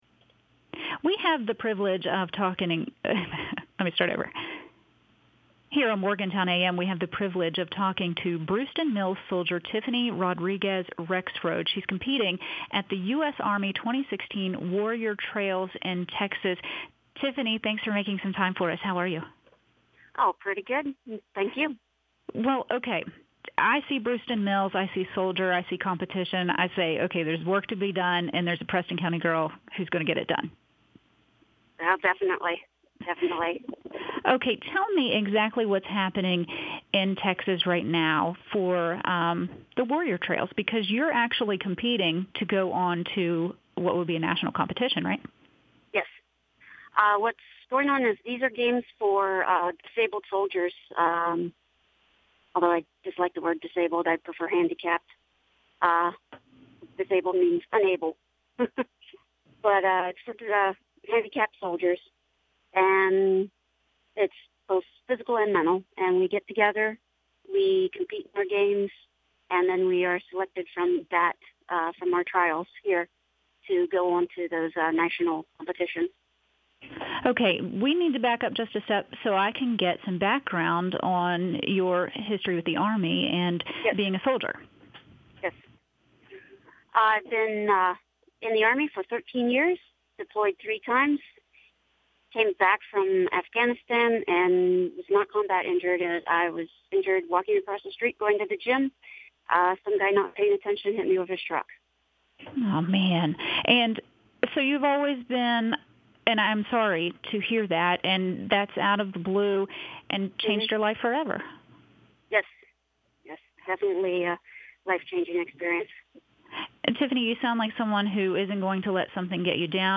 talks to a WAJR-AM reporter